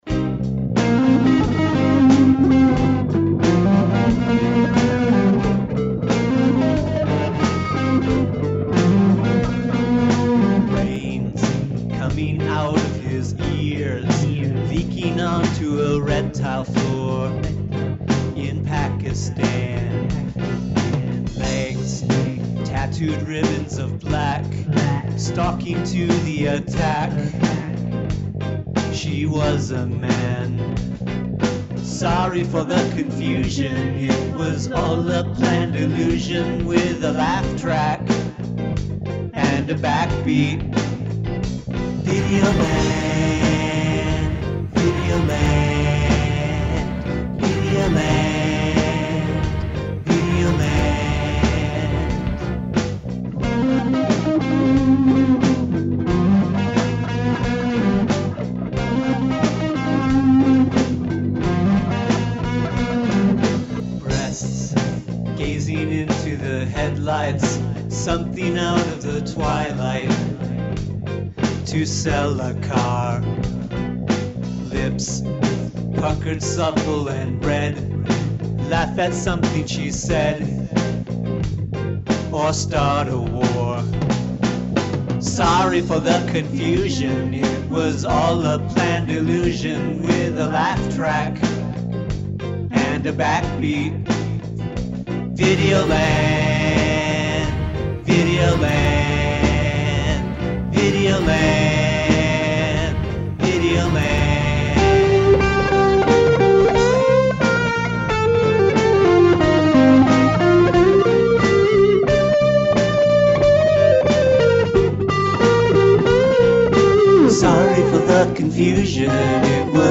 Must include a classical music sample
Cool guitar work!